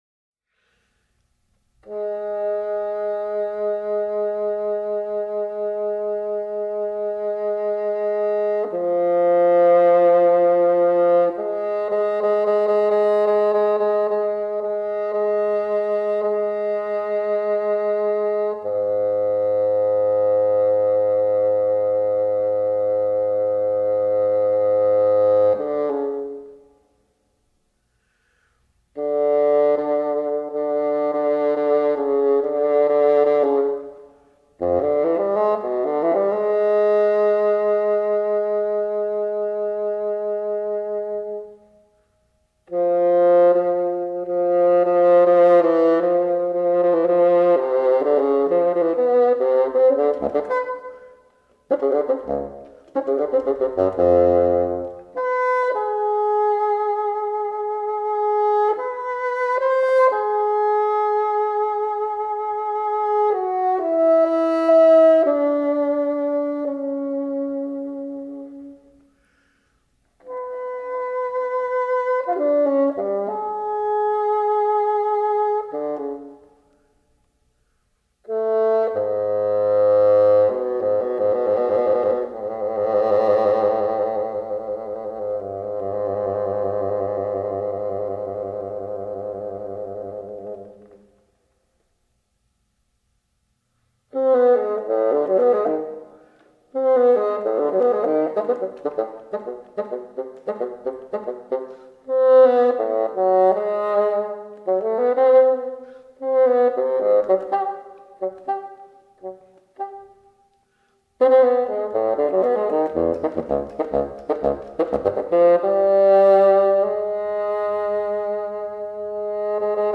for solo Bassoon